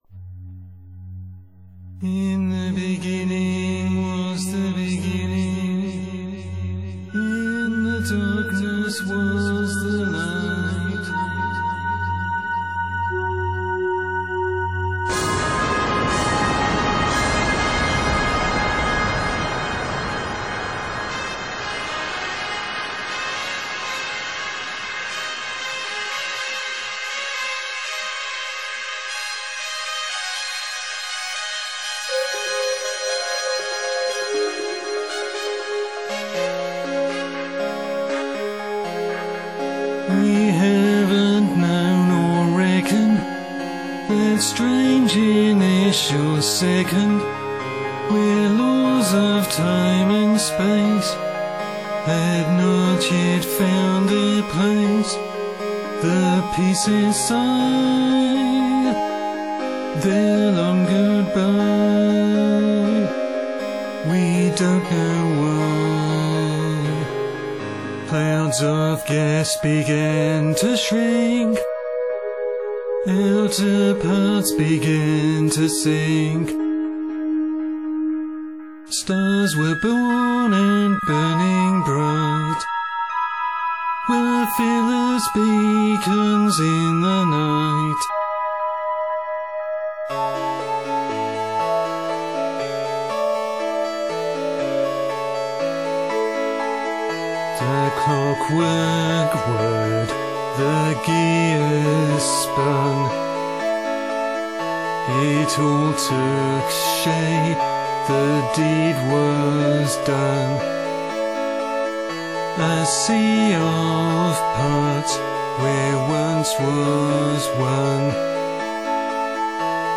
So I wrote a lyric trying to make the big bang sound poetic, and some time later tried to orchestrate it so that the backing musically described what was happening. Any musicians may notice successive key and time signature changes to symbolically mirror the dropping energy level in the expanding universe.